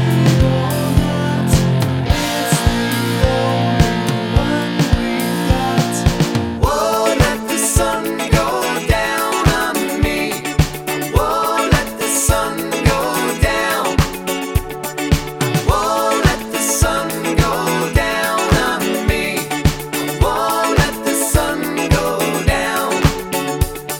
Minus Bass Pop (1980s) 3:18 Buy £1.50